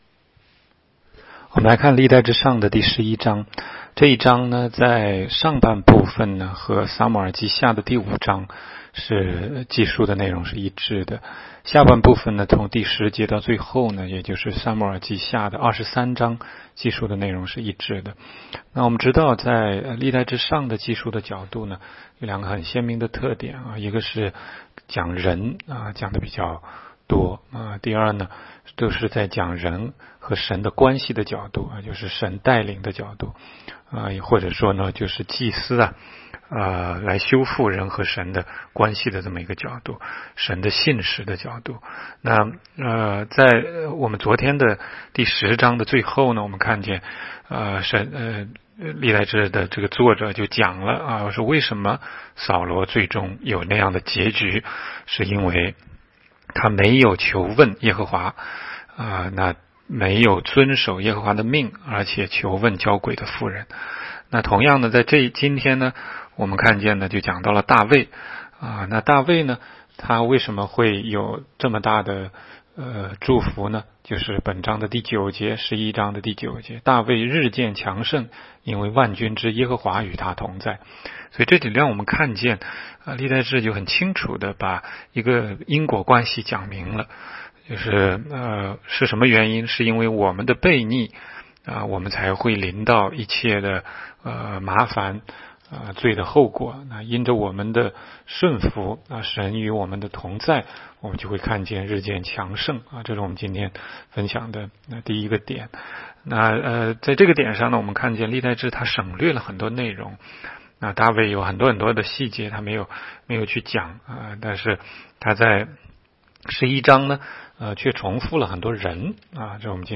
16街讲道录音 - 每日读经-《历代志上》11章